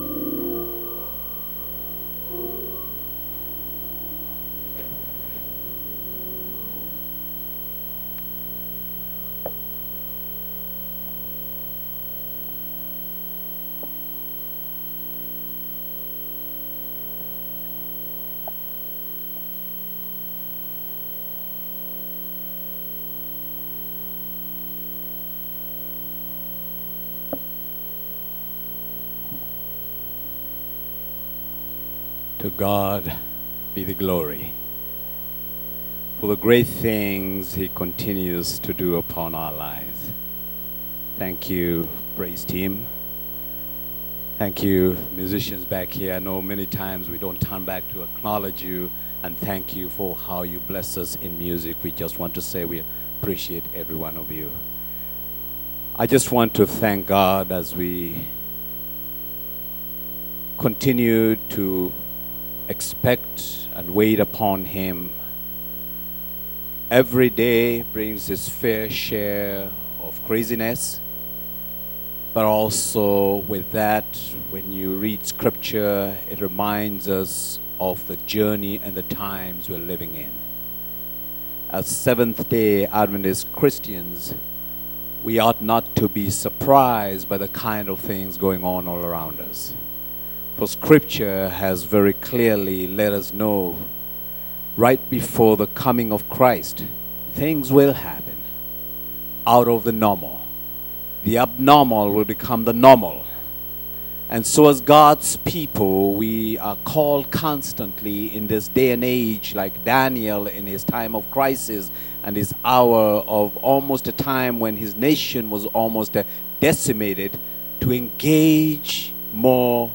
Sermons - Family Life Circle